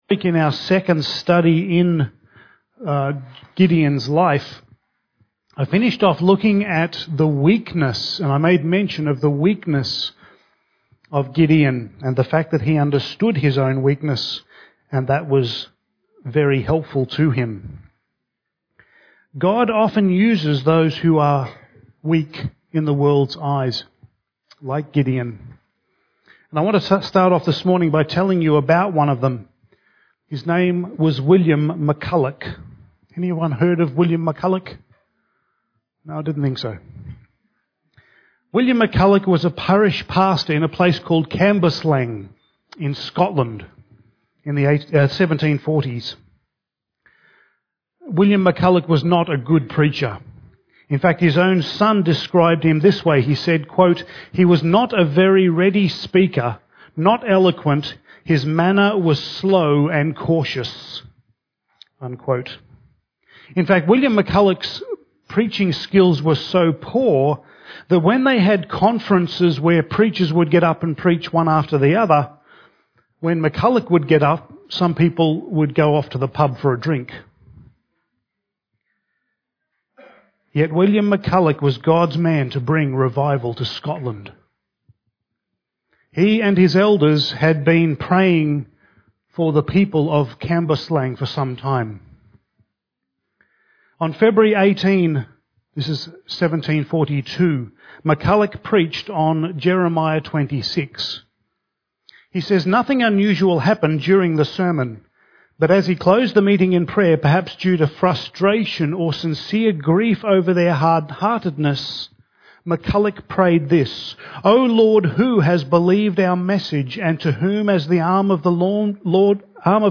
Passage: Judges 7:15-8:28 Service Type: Sunday Morning